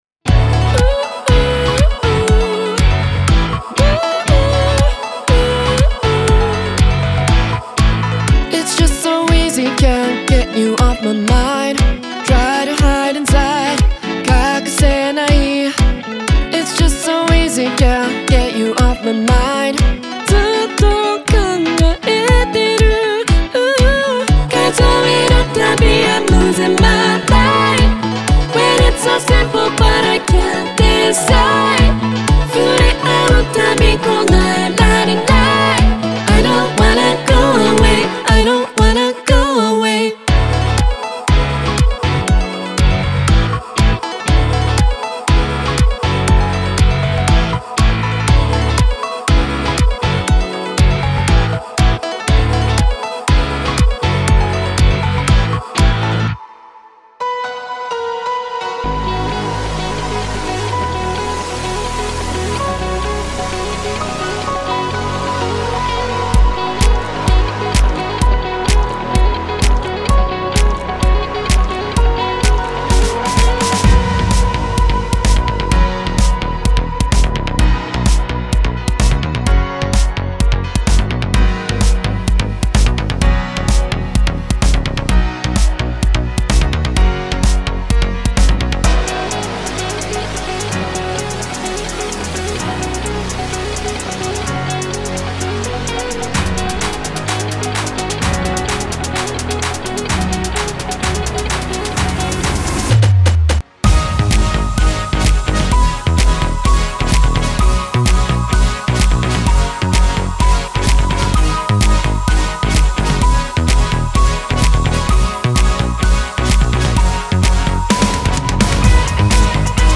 Genre:EDM
さらに、ドラムやエフェクトも揃っており、クリエイティブなオプションが尽きることはありません。
デモサウンドはコチラ↓
33 Synth Loops
16 Vocal Loops
12 Piano Loops
9 Guitar Loops